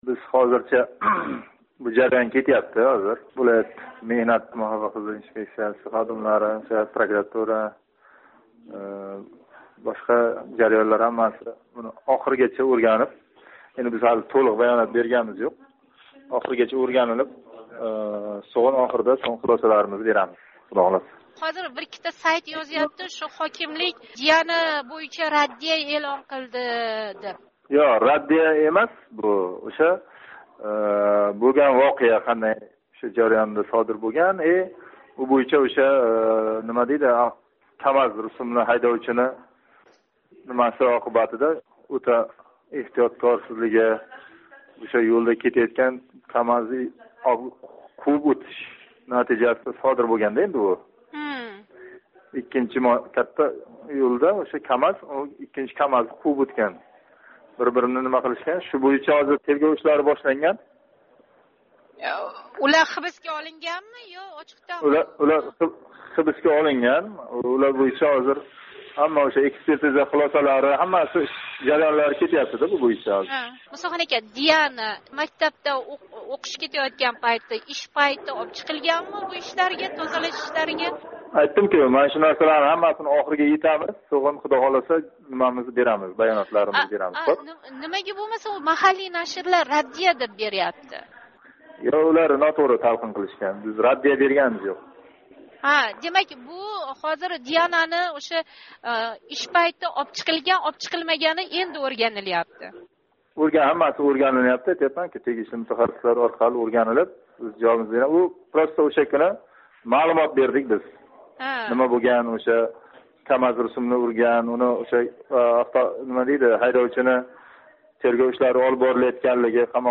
Каттақўрғон туман ҳокими ўринбосари Мусахон Музаффаров билан суҳбат